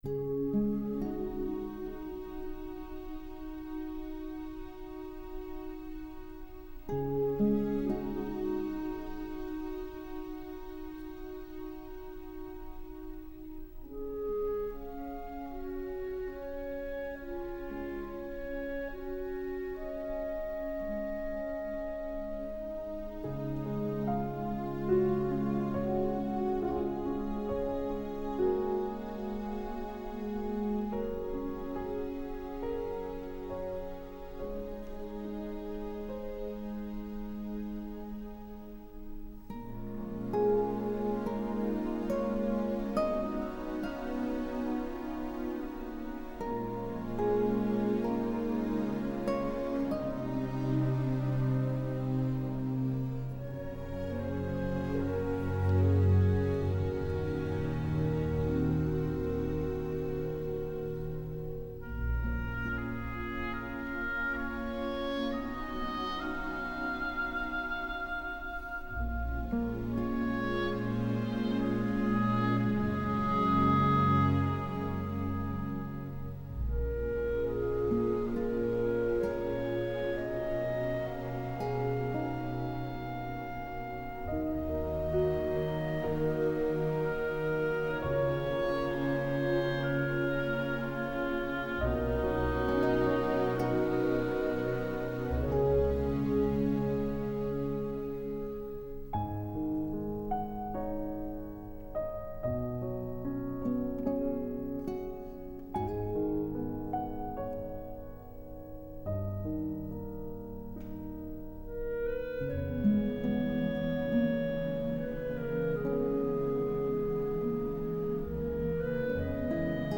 BSO